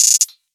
Closed Hats
MURDA_HAT_CLOSED_ROLL_DICE.wav